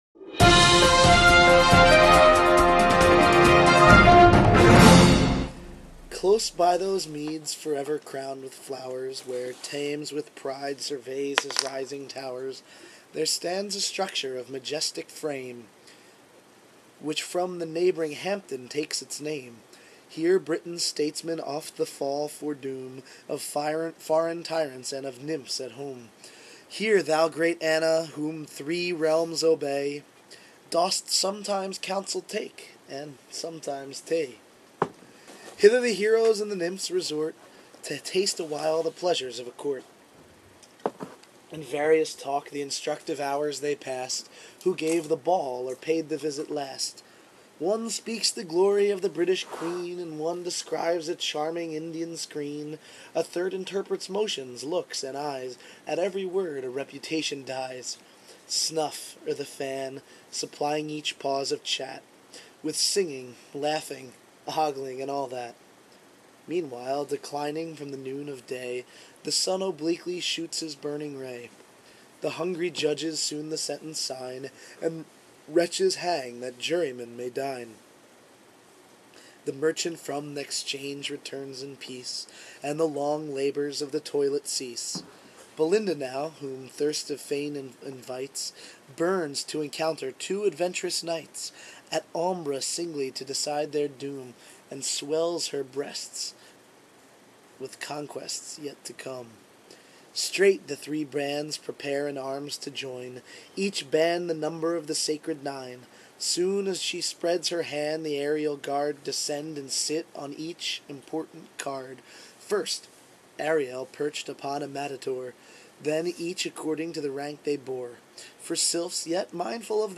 reads Canto 3 (of 5) from Alexander Pope's "Rape of the Lock," one of the most famous mock epics ever written.